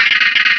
Cri de Branette dans Pokémon Rubis et Saphir.